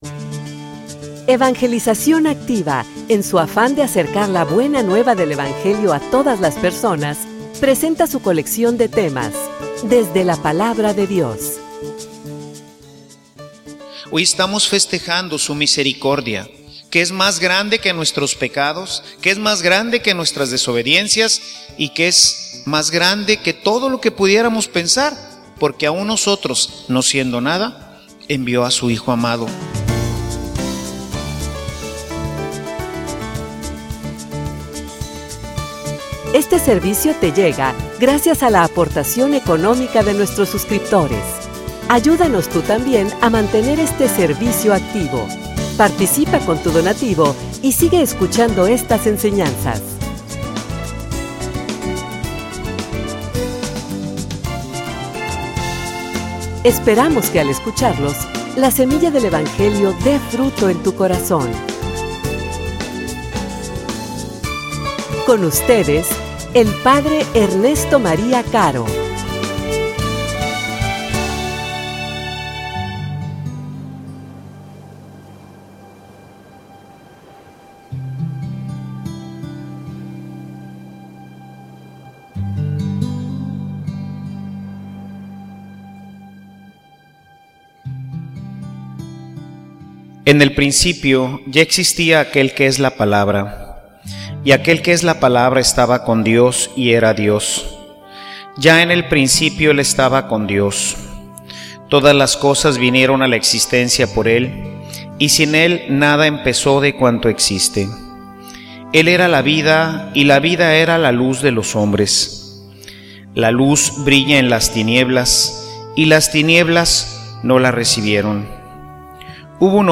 homilia_Nuestra_navidad.mp3